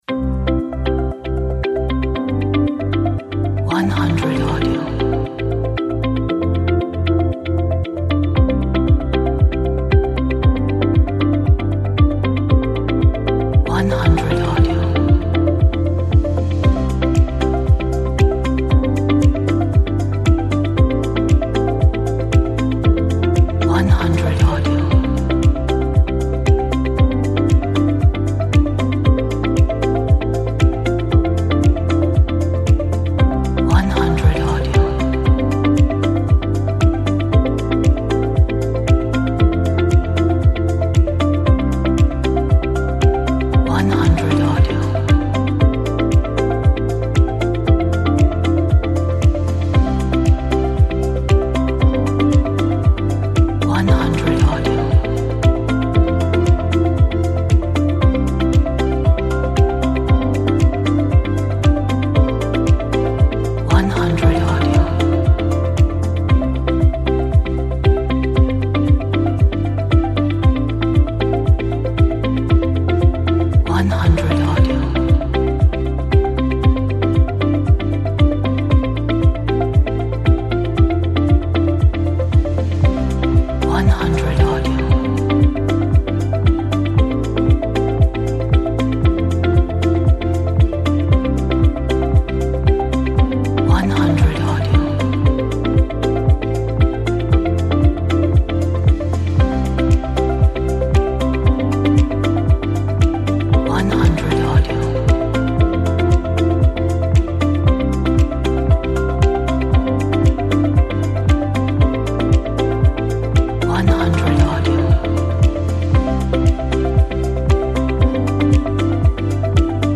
Corporate track.